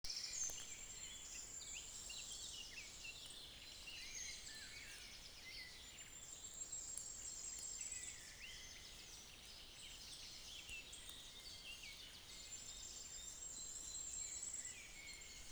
Baltabruvis nykštukas
(Regulus ignicapilla)
Paukščiai